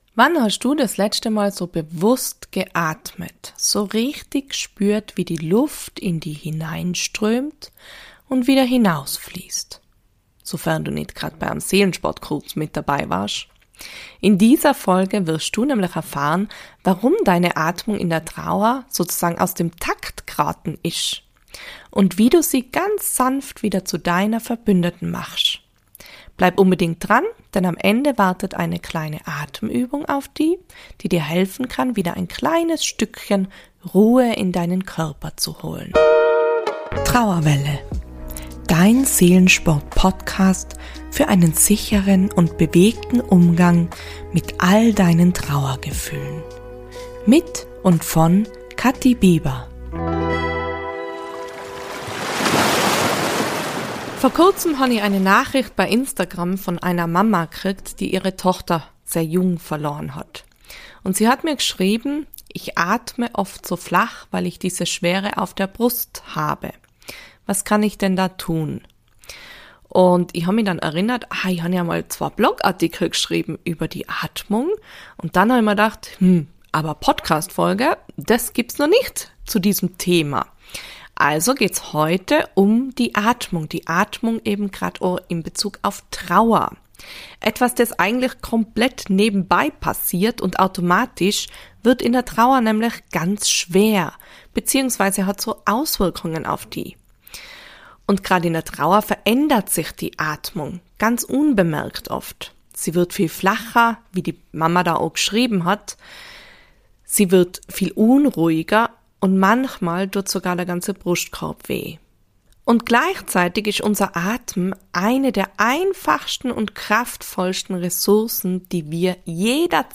Am Ende wartet eine einfache, geführte Atemübung auf dich, die du direkt mitmachen kannst.